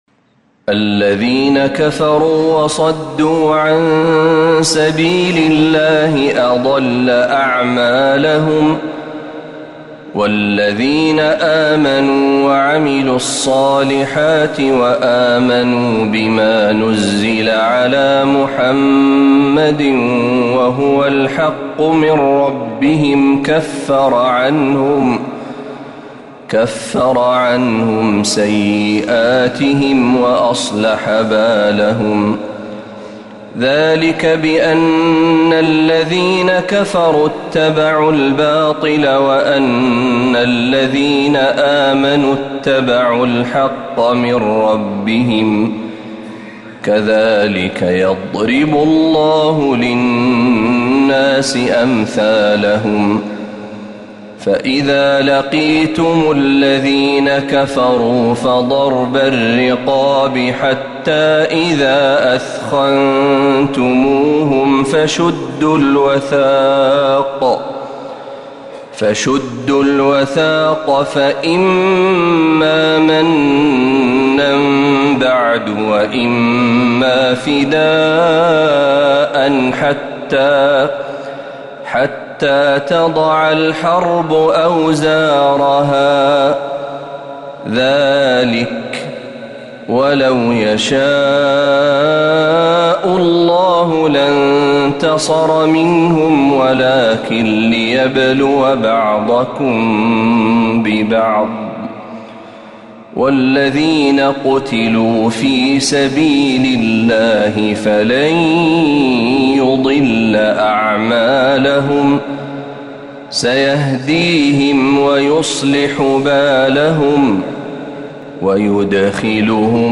تلاوات الحرمين